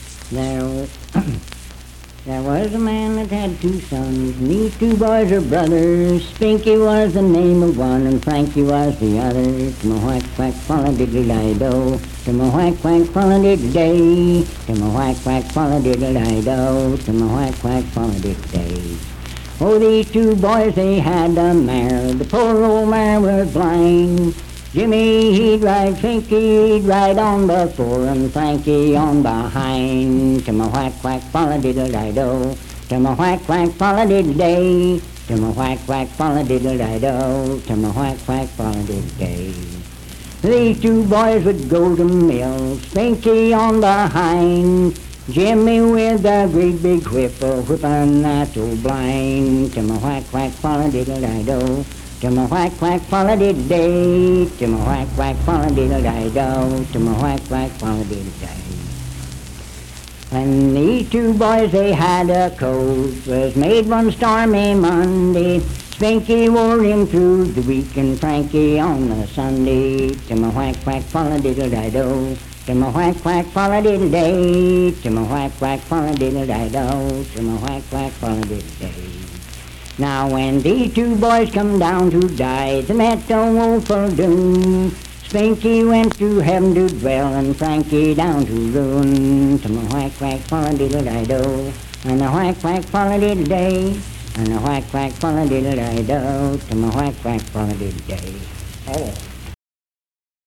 Unaccompanied vocal music
Performed in Sandyville, Jackson County, WV.
Voice (sung)